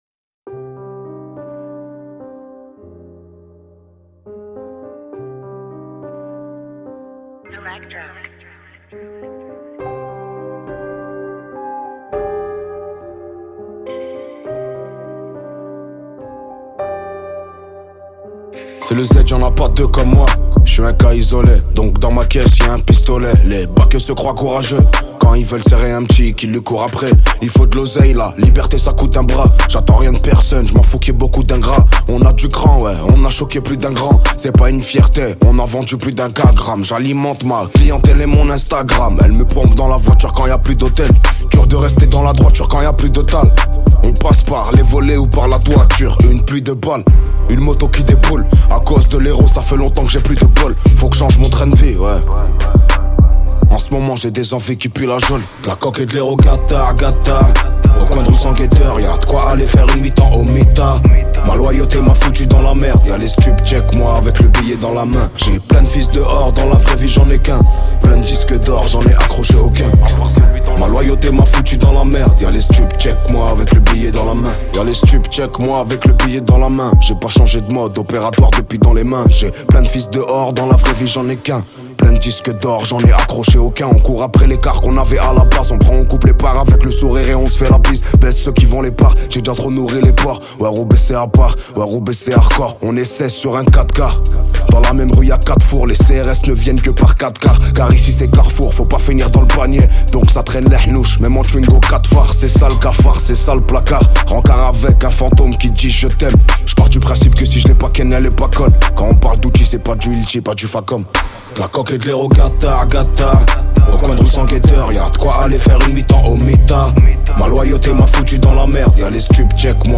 Genres : french rap, french r&b, pop urbaine